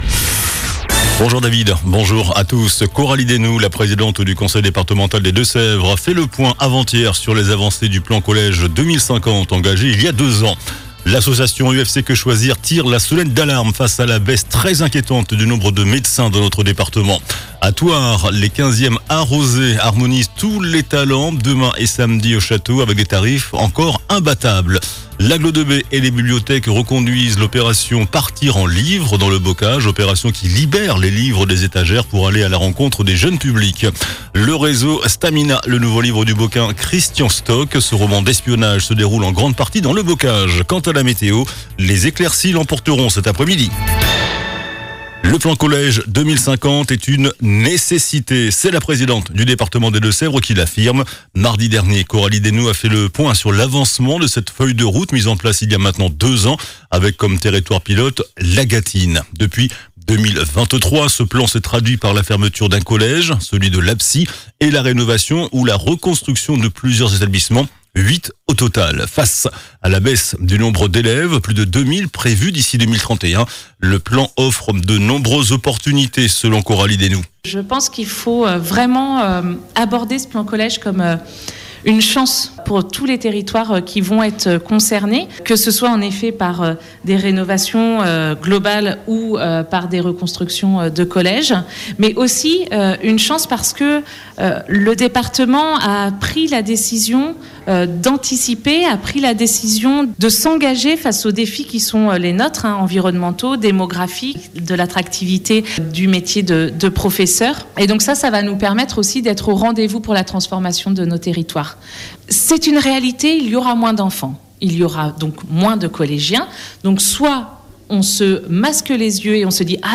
JOURNAL DU JEUDI 26 JUIN ( MIDI )